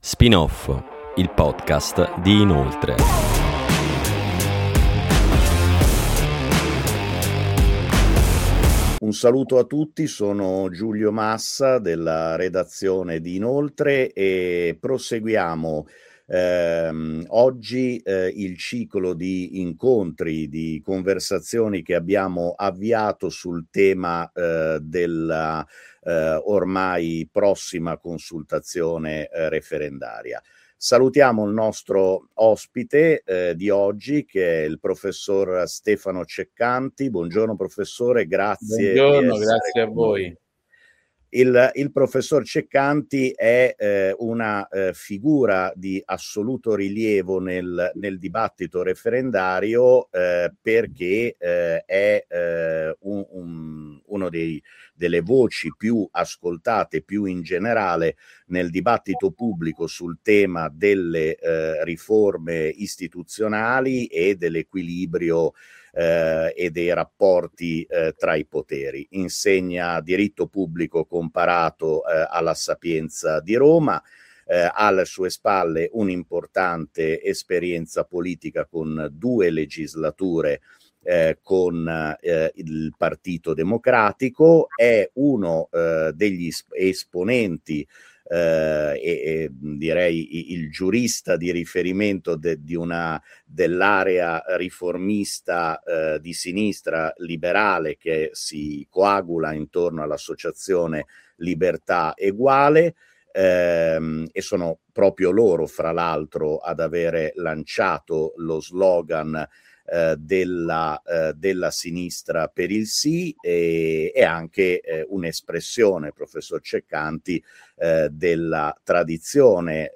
Ep. 3: Un Sì per una riforma liberale e di sinistra. Intervista a Stefano Ceccanti